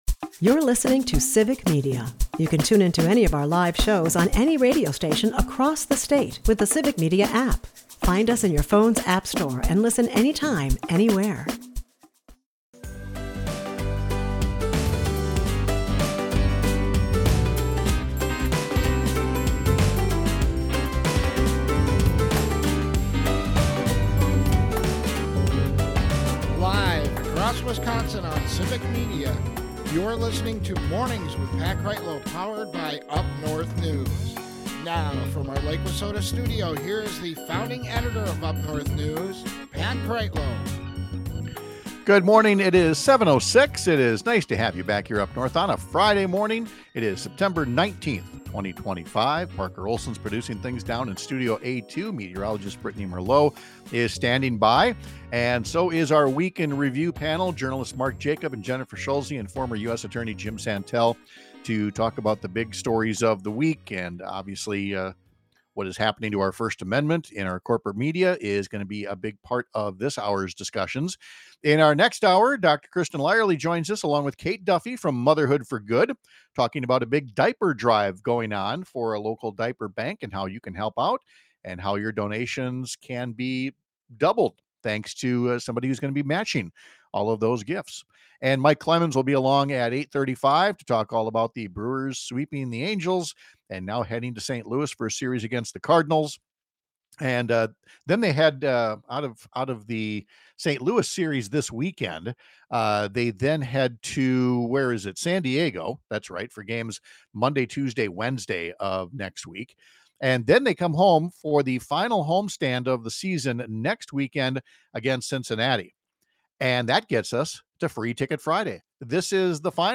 Our Week In Review panel has some thoughts on the latest capitulation from corporate media and how it has emboldened Donald Trump to outright threaten the licenses of broadcasters if he doesn’t like what they say about him.